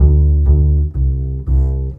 Rock-Pop 11 Bass 03.wav